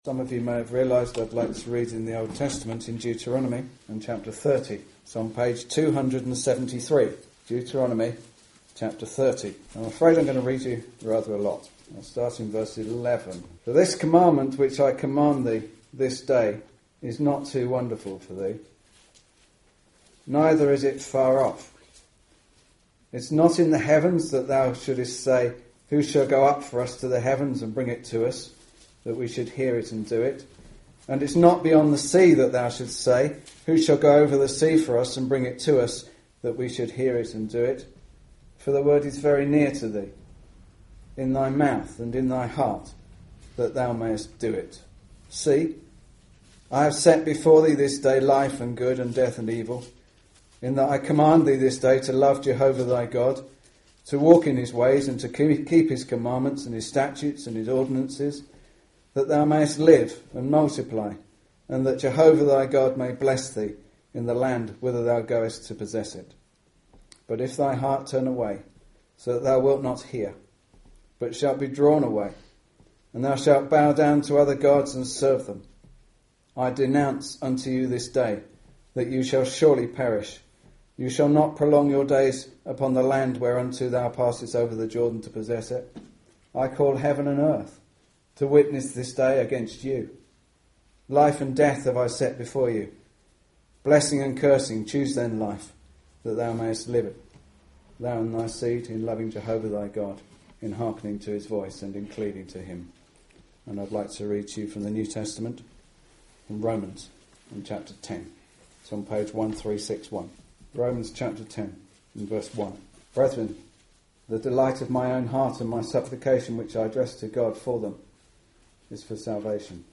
In this Gospel preaching, you will hear of how we can enter heaven through the living saviour the Lord Jesus Christ. There are many ideas in the world today of ways into heaven.